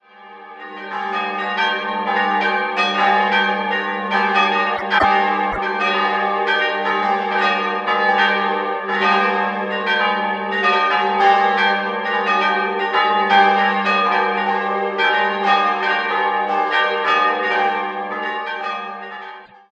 Sophienglocke g' 645 kg ??? mm 2005 Lauchhammer Glocke 2 as'+6 460 kg 946 mm 13. Jh. unbezeichnet Glocke 3 es''-1 136 kg 635 mm 1403 unbezeichnet Glocke 4 g''+2 170 kg 628 mm 14. Jh. unbezeichnet Glocke 5 b''+3 147 kg 570 mm 14. Jh. unbezeichnet